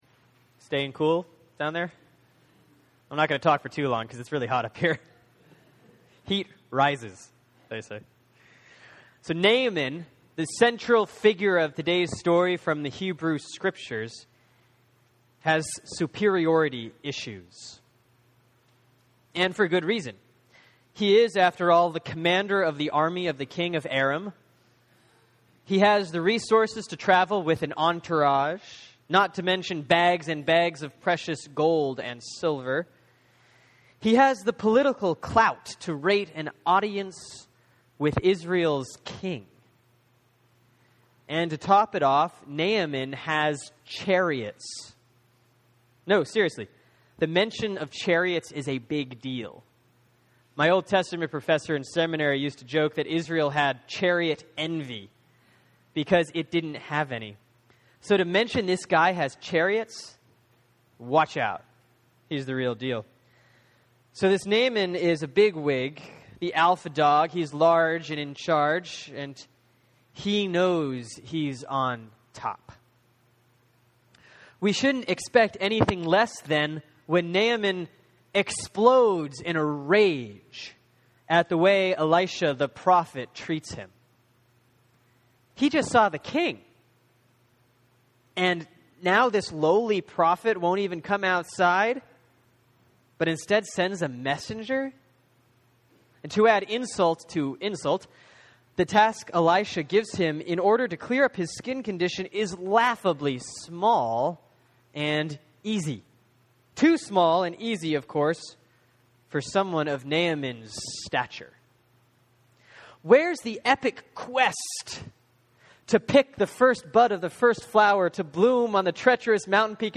(Sermon for Sunday, July 7, 2013 || Proper 9C || 2 Kings 5:1-14)